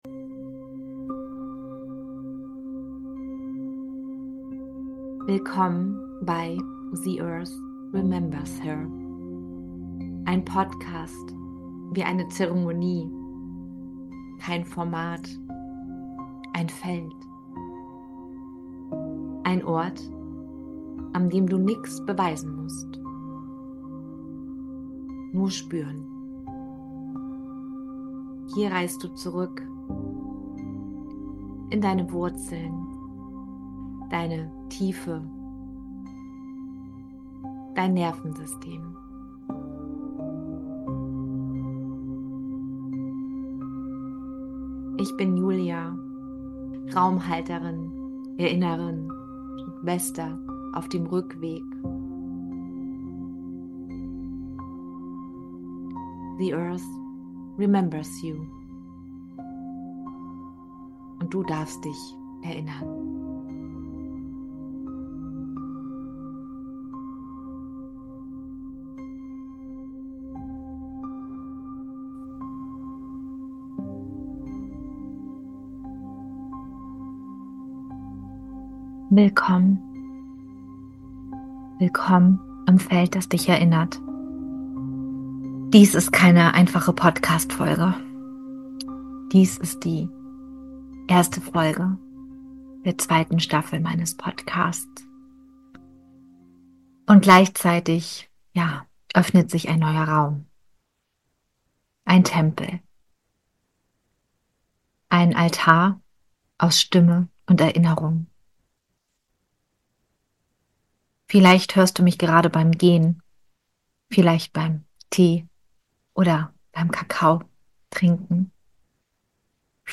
Geführte Meditation: Rückverbindung mit deinem inneren Licht Persönliche Erinnerungen aus meiner Basic DNA- & Intuitive Anatomie-Ausbildung Sanfte Worte für dein Nervensystem – und dein Herz Wenn du tiefer eintauchen willst:  Kostenfreies ThetaHealing Intro 23.